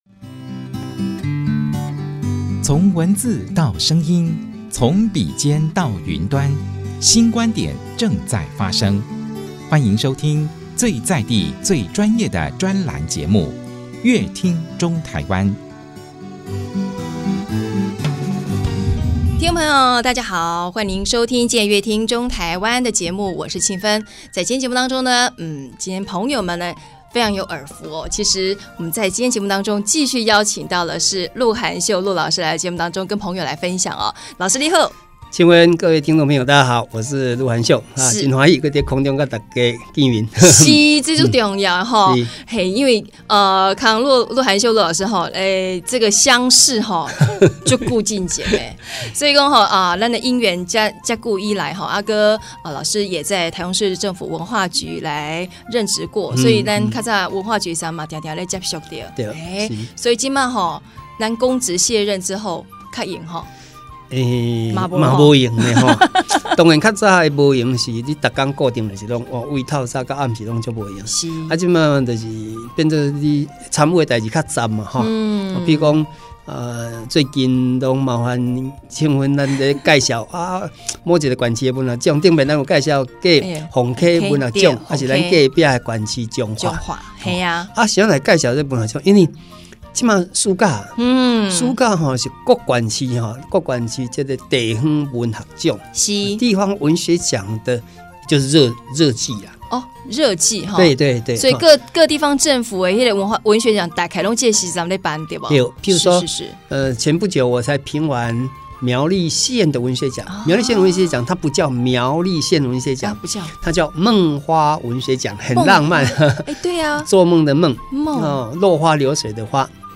本集來賓：文學大師路寒袖老師 本集主題：「『呷臺南』用美食豐盈臺南文學獎」